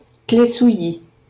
Claye-Souilly (French pronunciation: [klɛ suji]